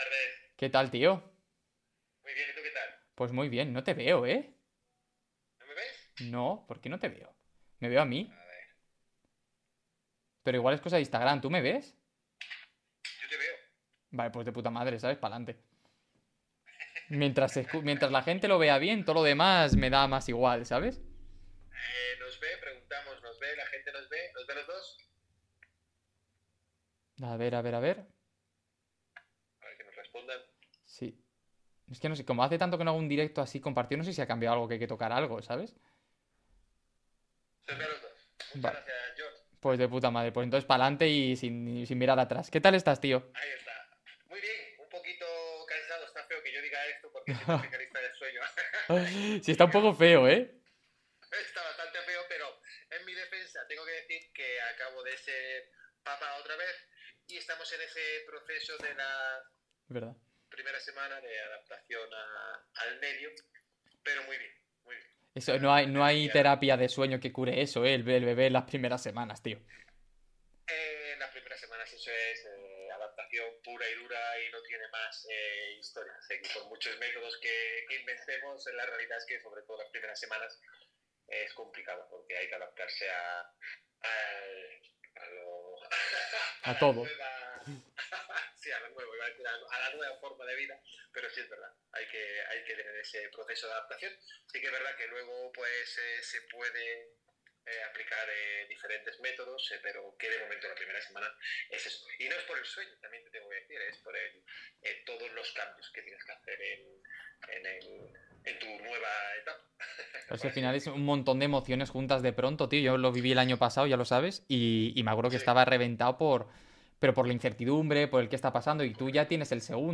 Hoy toca entrevista a un especialista del sueño que nos cuente cómo cuidar nuestro sueño para mejorar nuestra creatividad.